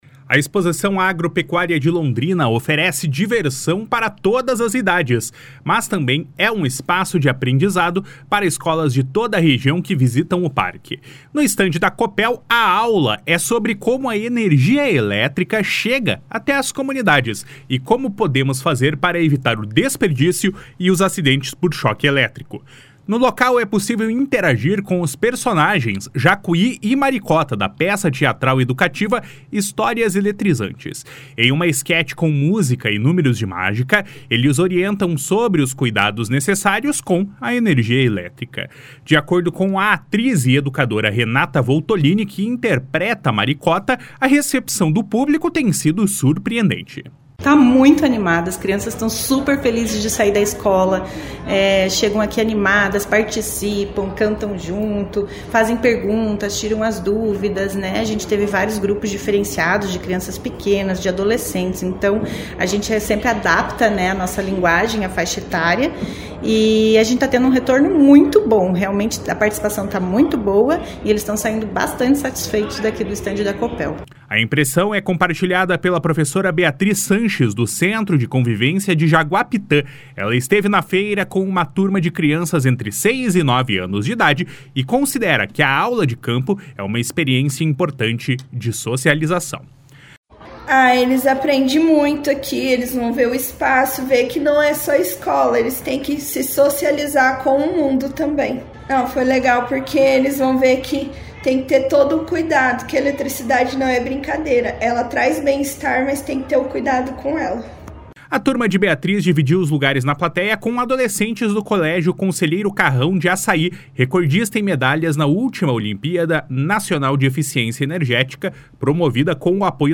Até o fim desta sexta-feira, cerca de 10 mil crianças e adolescentes devem ter passado pelo espaço. (Repórter: